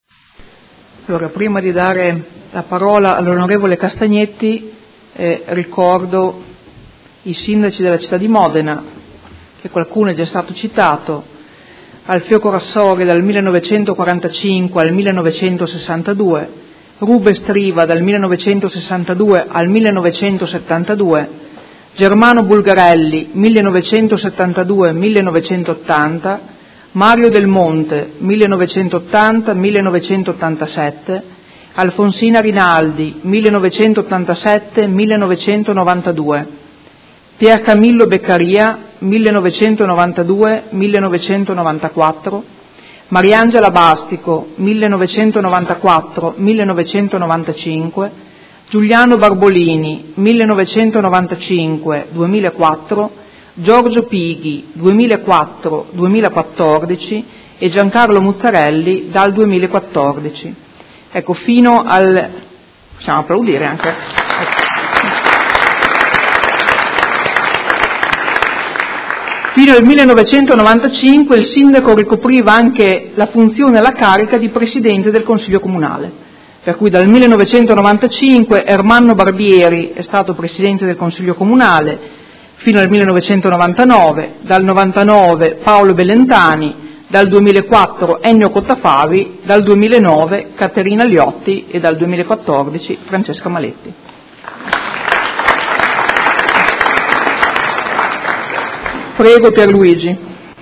Seduta del 20/04/2016. Dibattito su Celebrazione del 70° dall'insediamento del primo Consiglio Comunale di Modena dopo il periodo fascista.